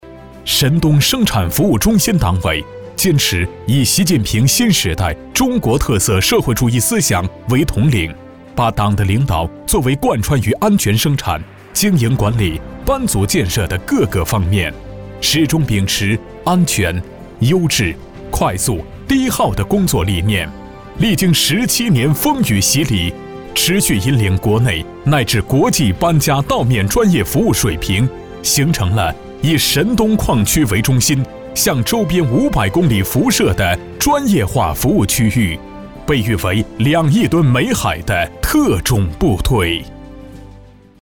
大气浑厚 企业专题,人物专题,医疗专题,学校专题,产品解说,警示教育,规划总结配音
大气浑厚男中音，偏年轻，稳重洪亮。擅长专题，宣传片等。作品：碧桂园、社区教育。